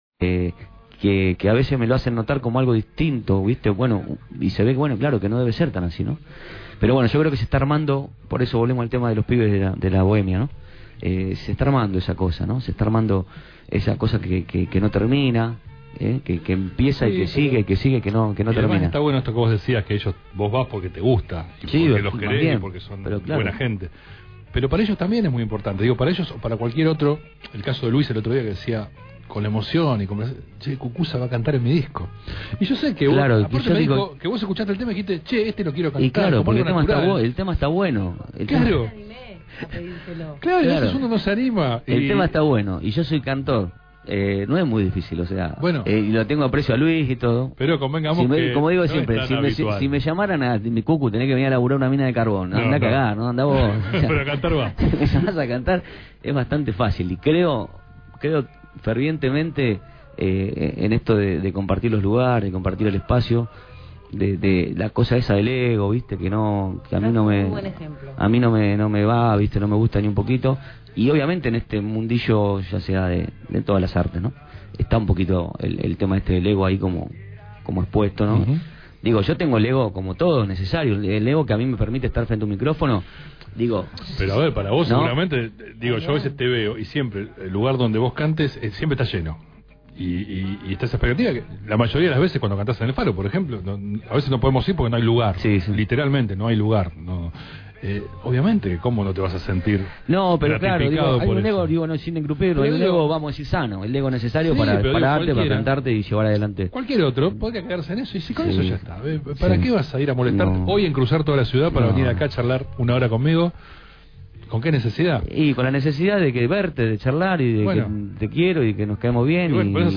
¡a capella!